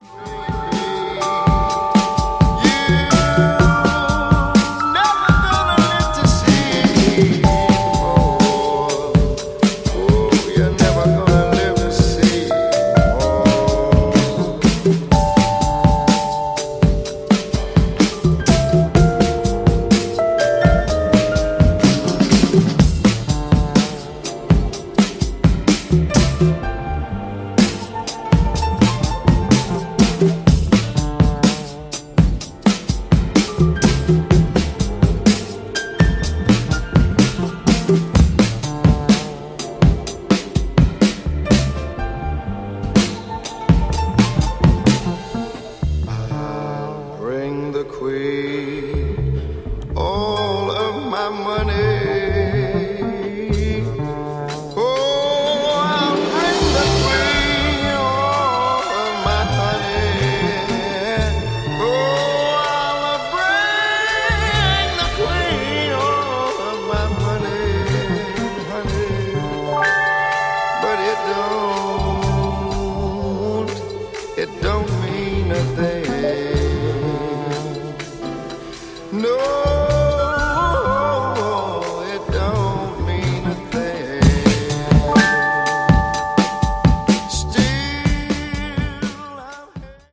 ジャンル(スタイル) HOUSE / CROSSOVER